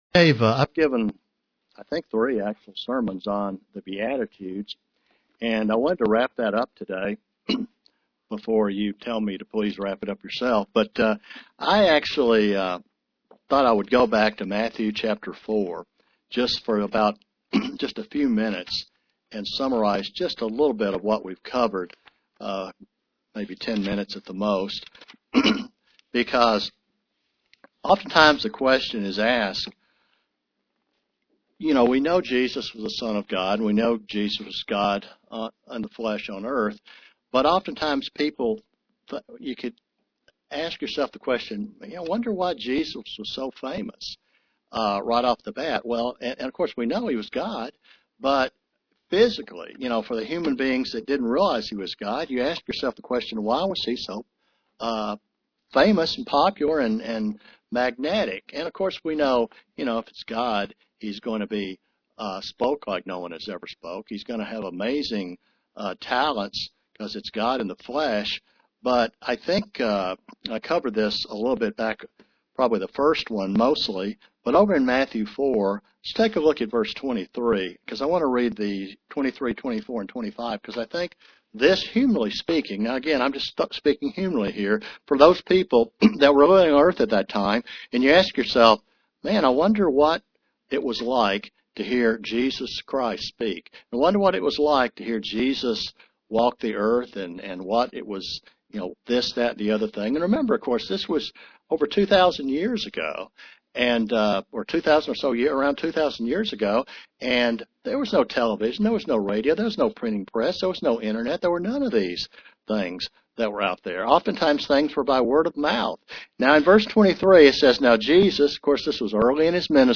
Print Concluding study of the beatitudes UCG Sermon Studying the bible?